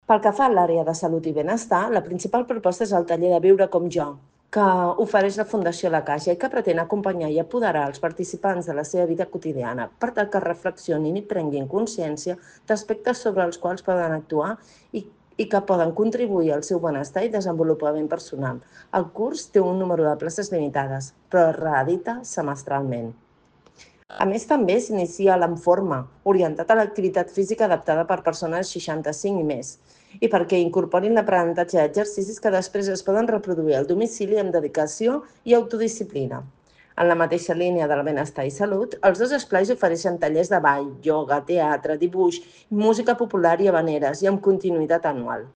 Montserrat Salas, regidora de Gent Gran de l'Ajuntament